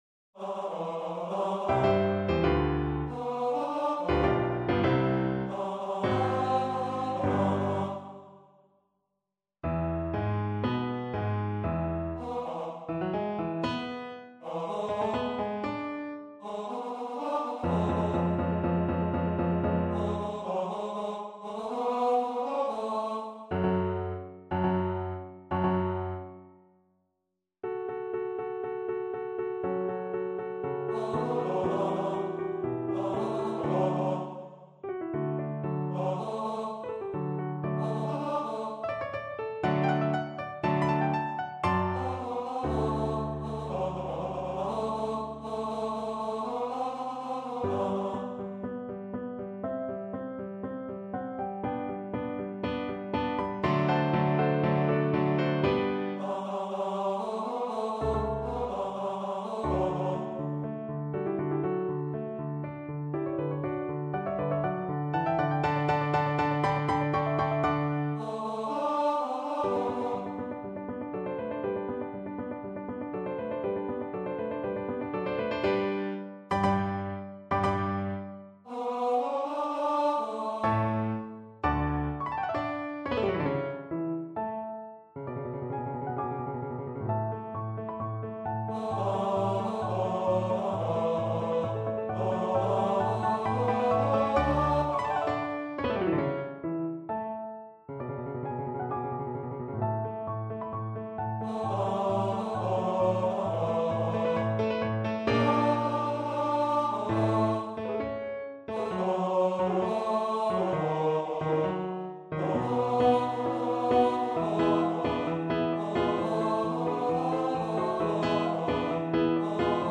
4/4 (View more 4/4 Music)
Maestoso
Classical (View more Classical Baritone Voice Music)